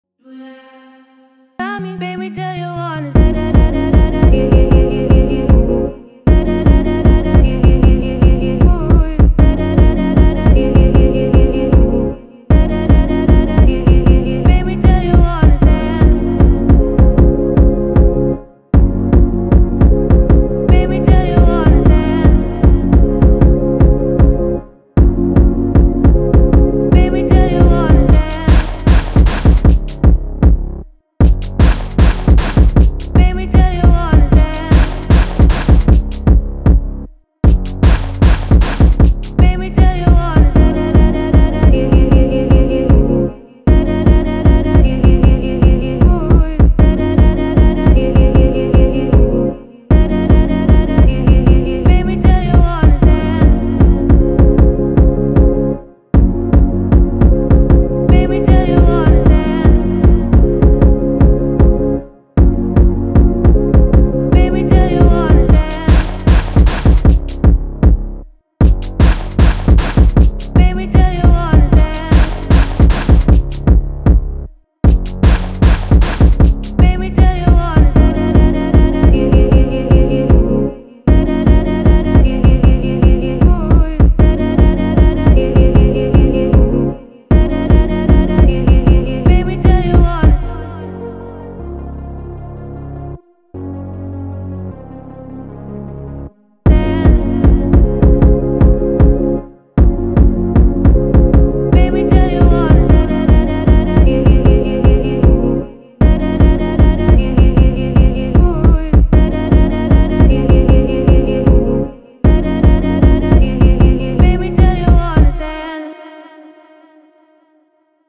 Инструментальная композиция